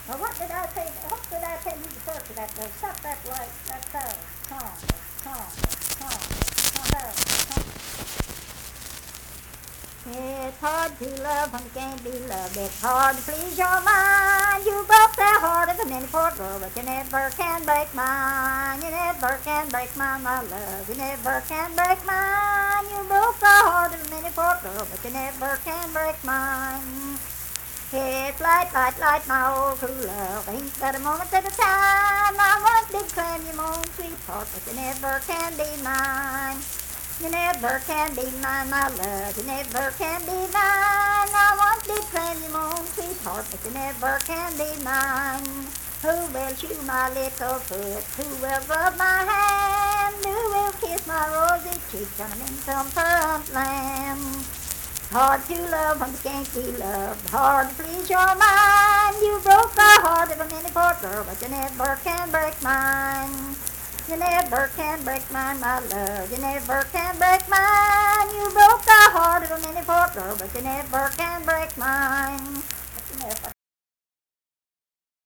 Unaccompanied vocal music performance
Verse-refrain 4(4w/R).
Voice (sung)